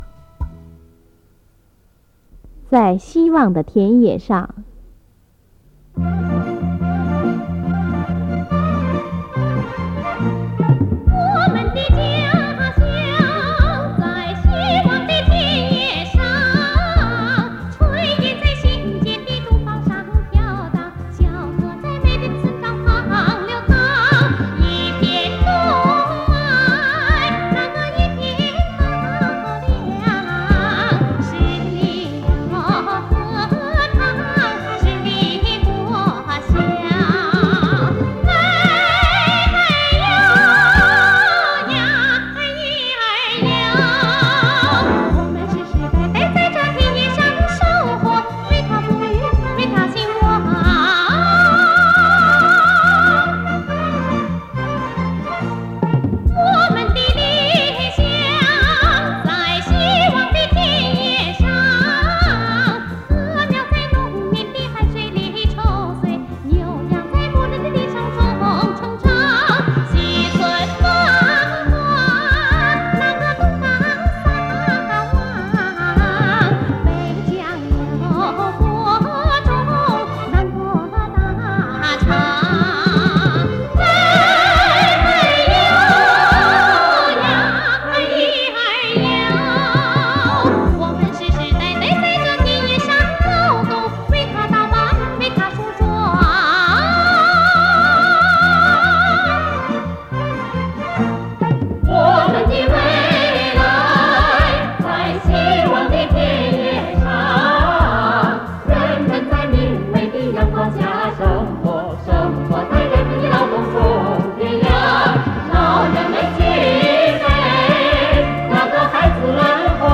（领、合唱）